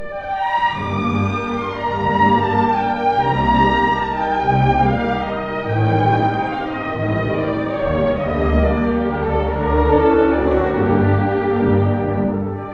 ↑古い録音のため聴きづらいかもしれません！（以下同様）
第3楽章｜スラブ舞曲風のワルツ
この楽章は「アレグレット・グラツィオーソ」。
優雅なワルツ風の音楽です。